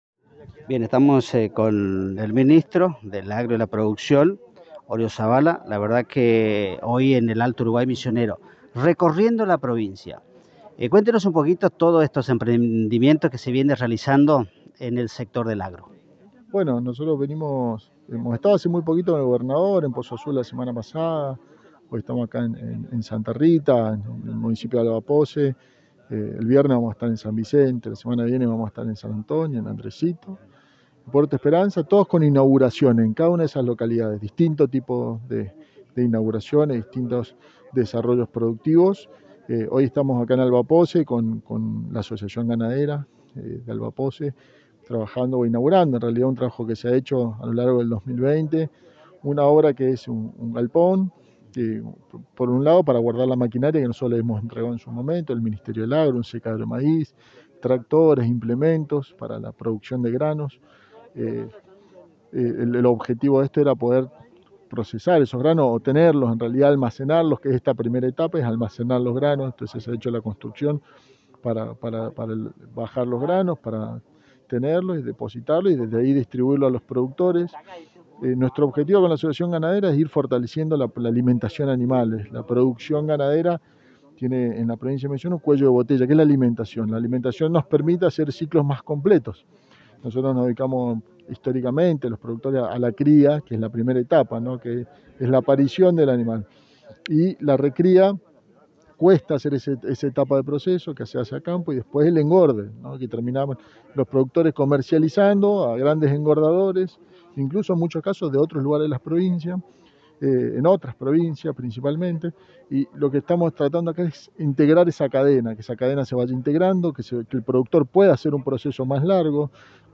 Audio: Ministro del Agro y la Producción Sebastián Oriozabala